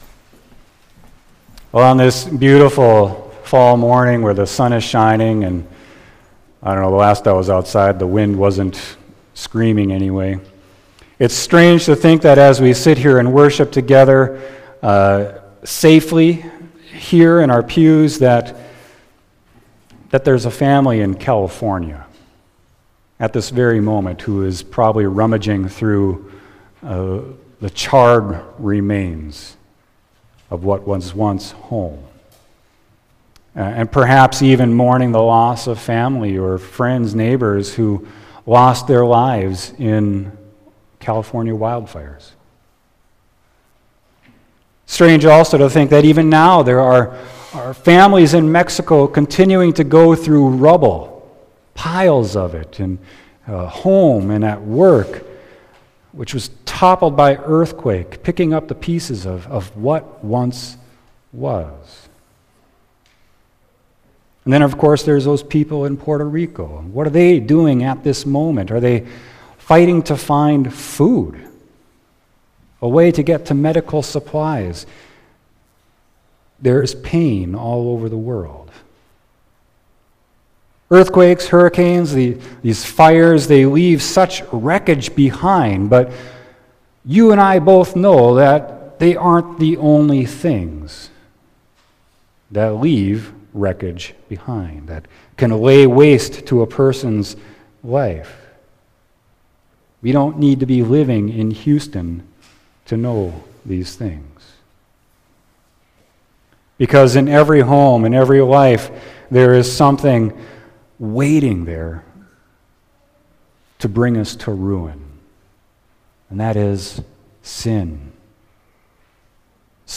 Sermon: Isaiah 45.1-7